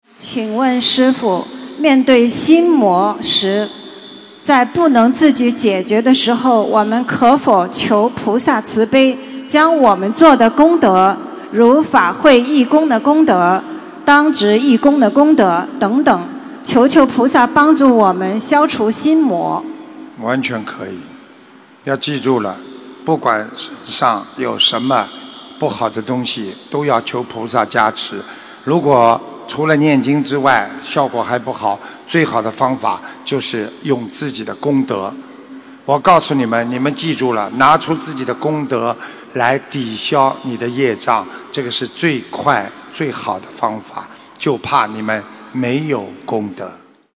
能否用功德求菩萨消除心魔┃弟子提问 师父回答 - 2017 - 心如菩提 - Powered by Discuz!